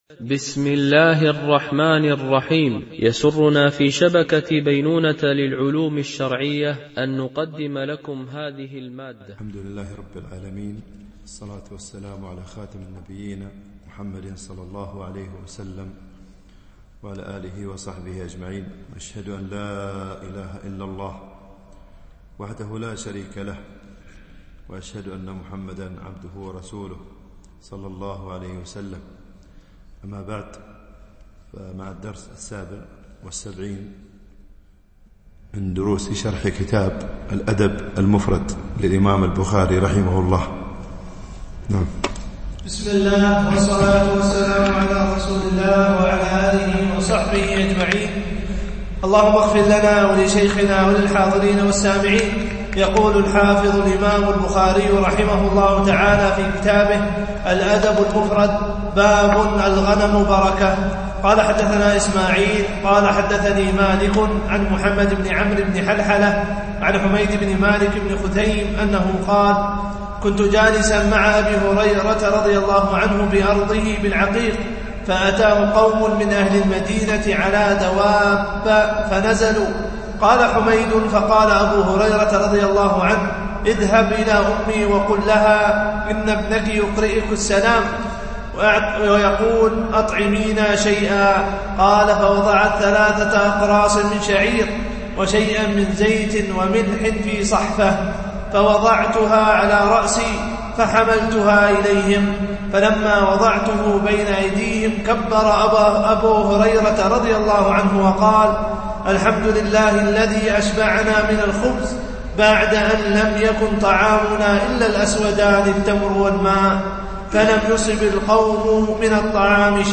شرح الأدب المفرد للبخاري ـ الدرس 77 ( الحديث 572 -577)